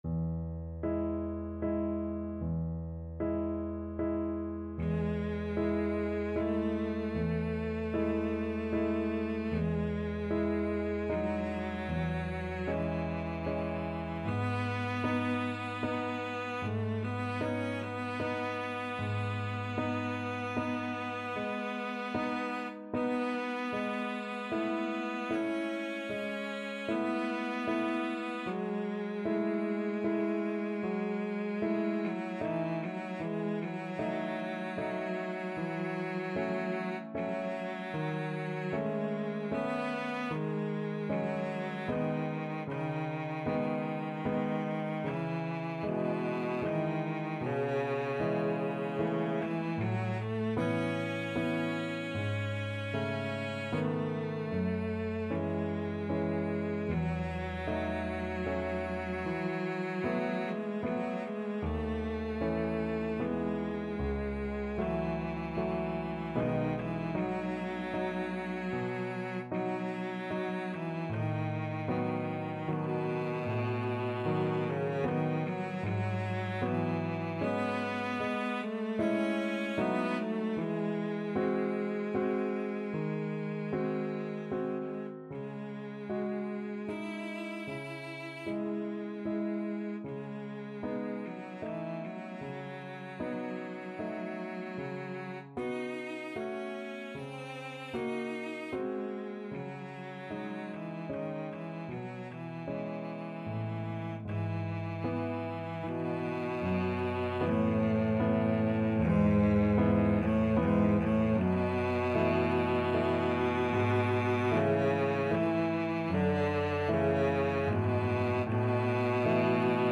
3/4 (View more 3/4 Music)
Adagio assai =76
Cello  (View more Intermediate Cello Music)
Classical (View more Classical Cello Music)